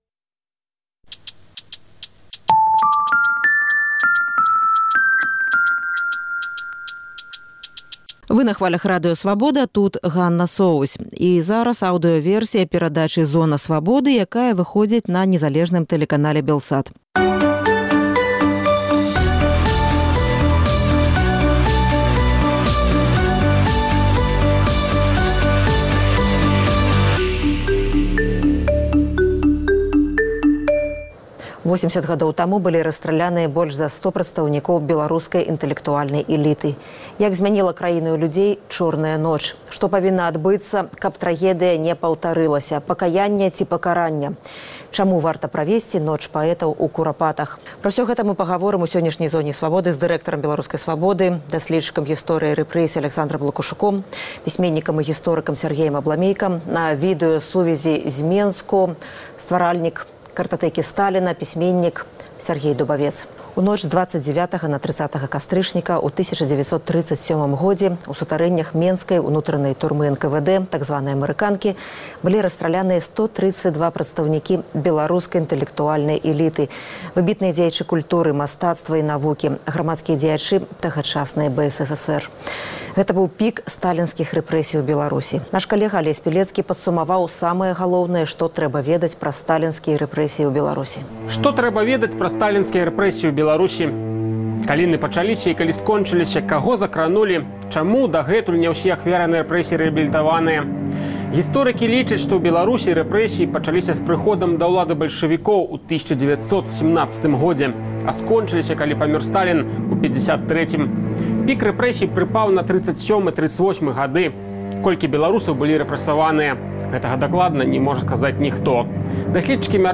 Абмяркоўваюць у Зоне Свабоды